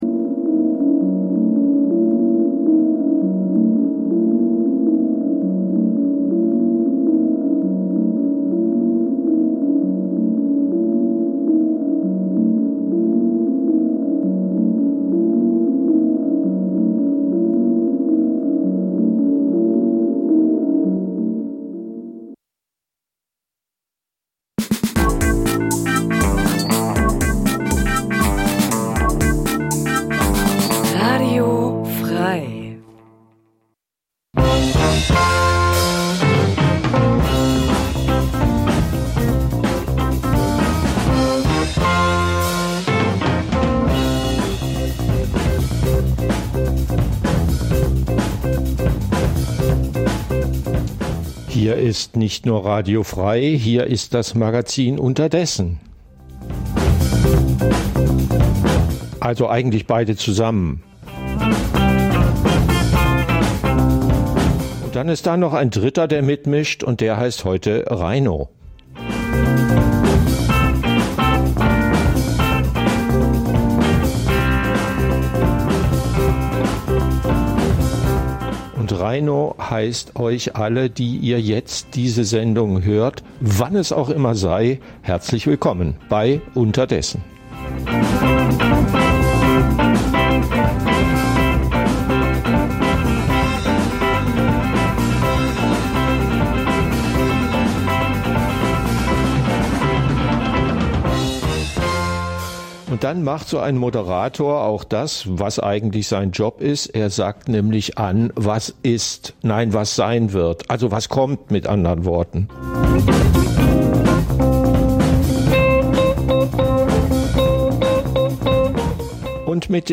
Das tagesaktuelle Livemagazin sendet ab 2024 montags bis freitags 9-11 Uhr.